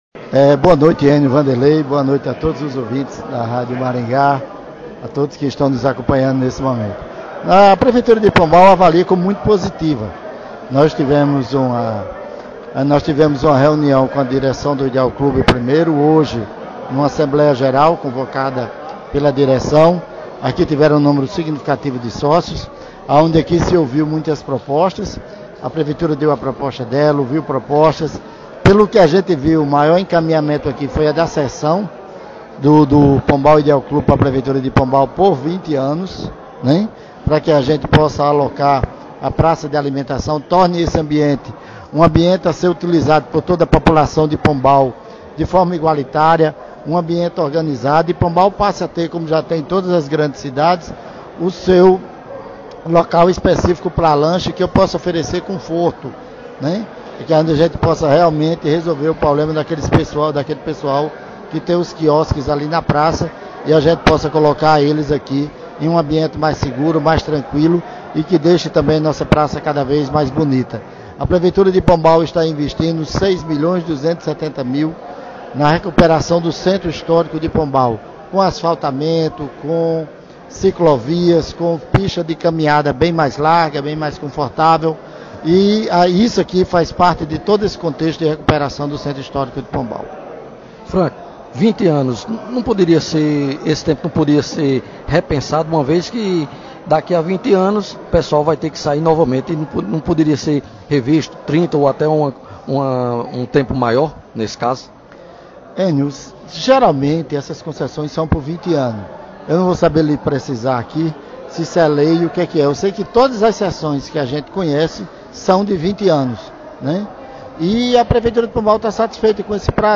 O secretário de Indústria e Comércio do município, Franco Vieira, se mostrou confiante num desfecho positivo.